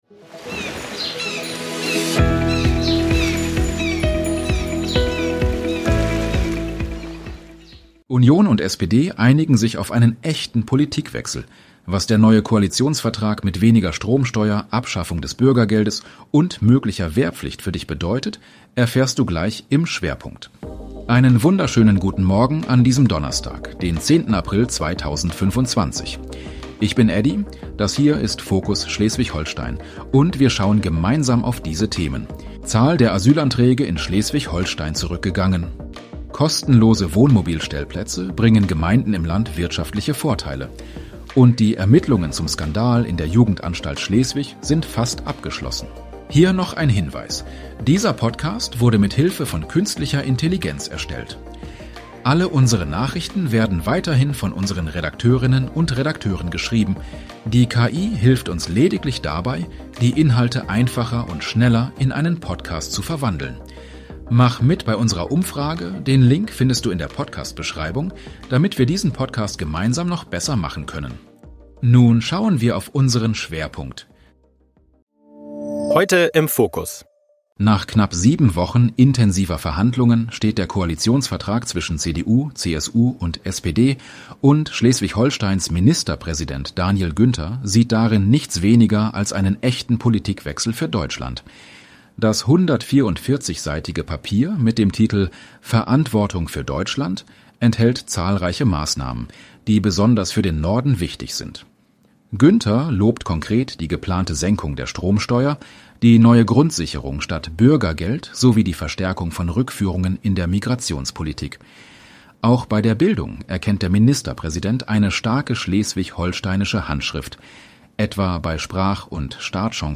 unserem regionalen Nachrichten-Podcast bekommst Du ab 7 Uhr die